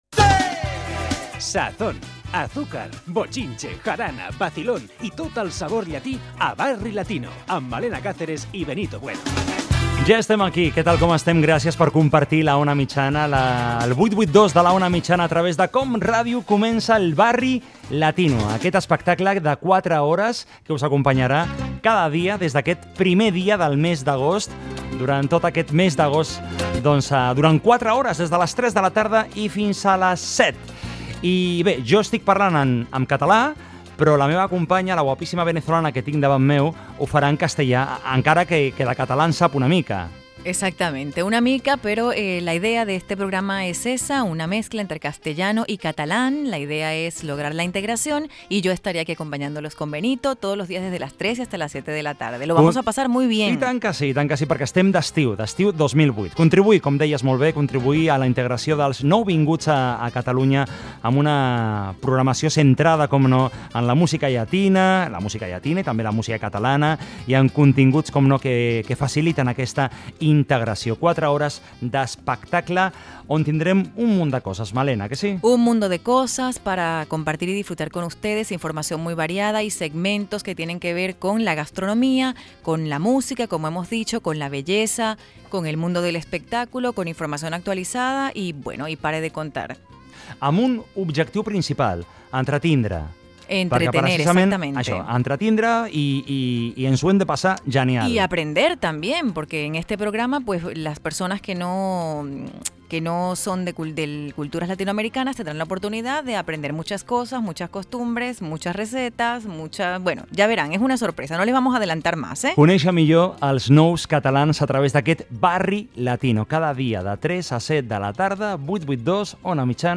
Careta, presentació de la primera edició del programa d'estiu i sumari.
Entreteniment
Fragment extret de l'arxiu sonor de COM Ràdio